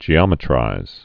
(jē-ŏmĭ-trīz)